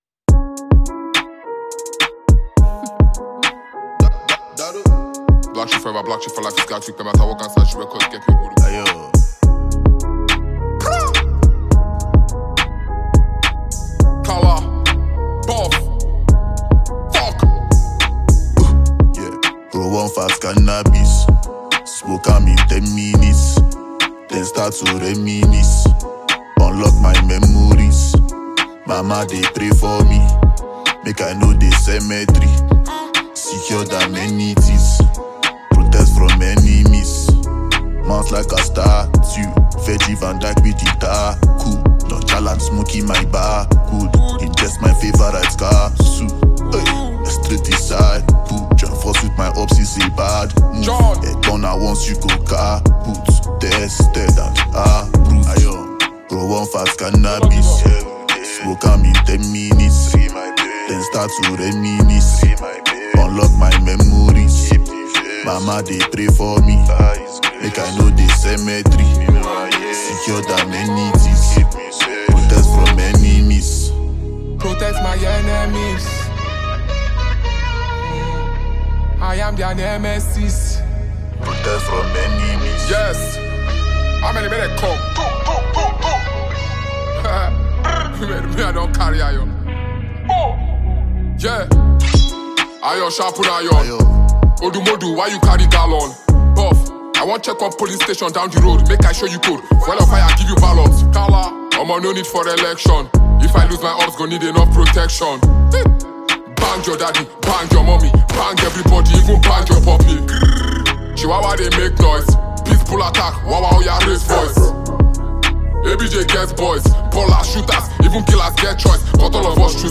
rapper and talented artist